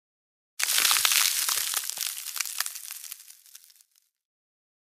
Ice Crack Eff Bouton sonore